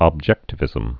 (ŏb-jĕktə-vĭzəm)